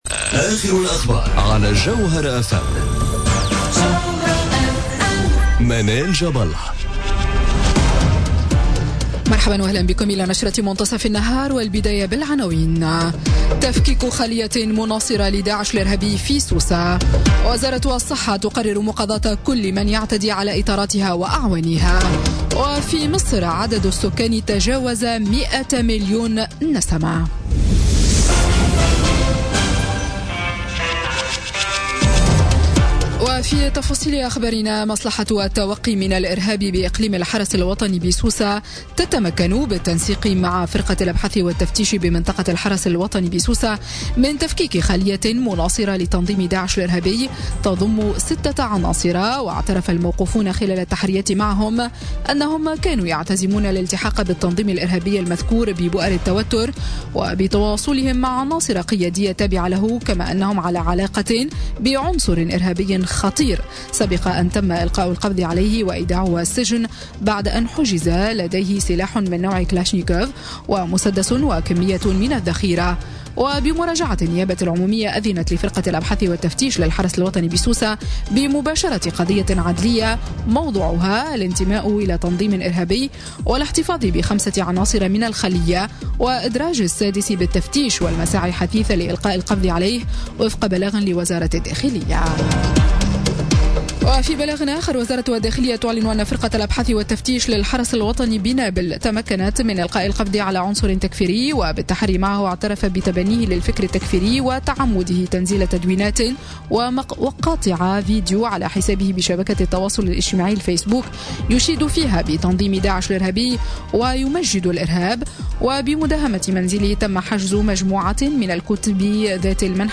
نشرة أخبار منتصف النهار ليوم السبت 30 أكتوبر 2017